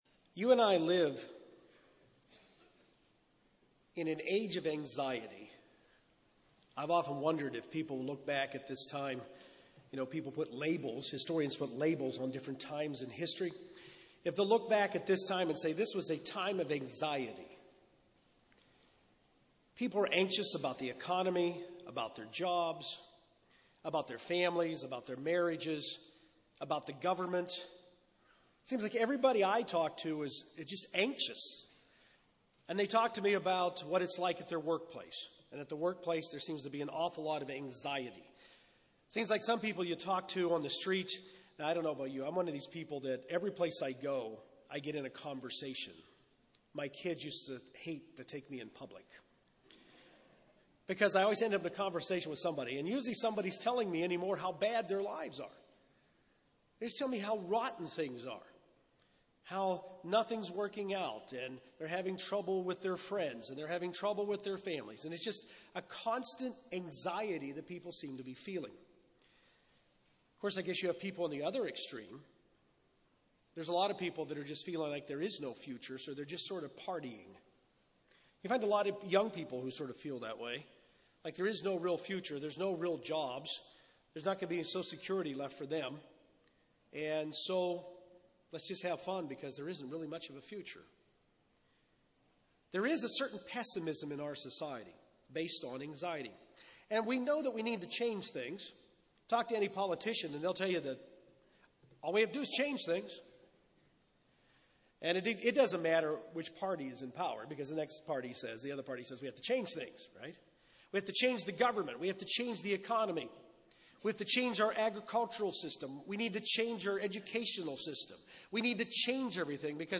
Learn how these current times fit with Bible prophecy in this Kingdom of God seminar.